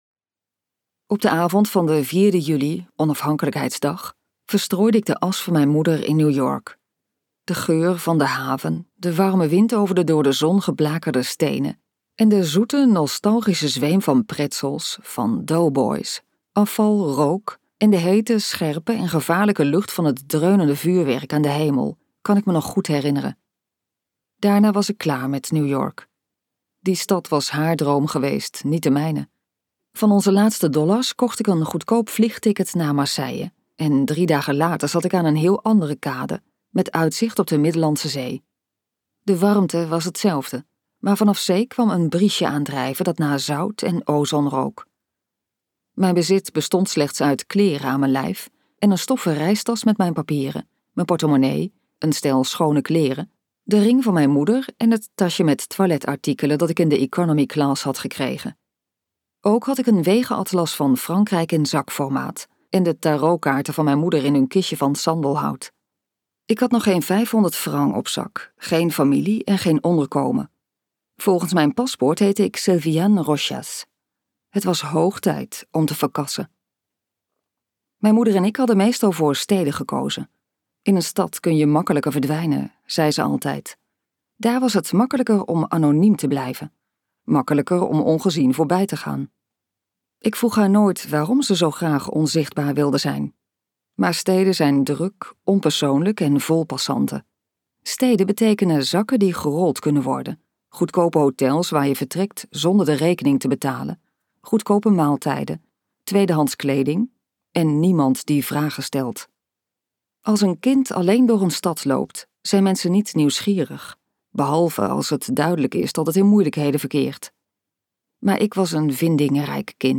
Uitgeverij De Fontein | Vianne luisterboek